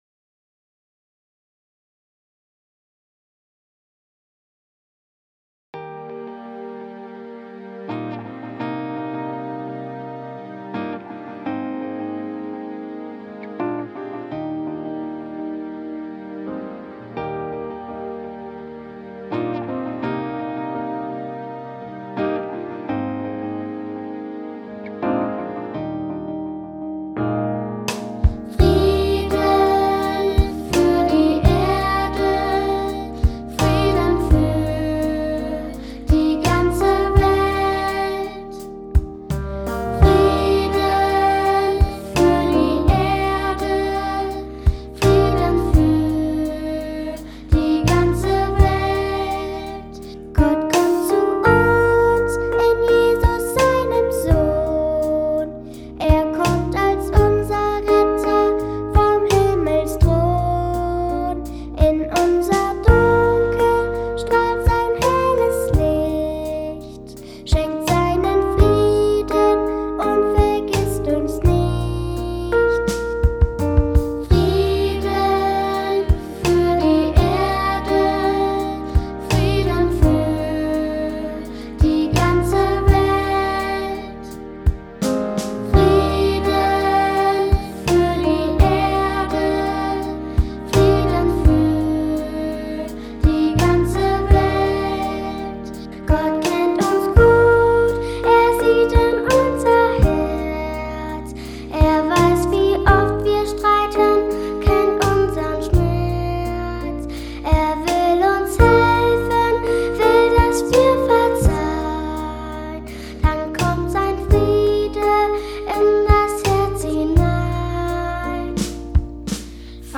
Lied zum Krippenspiel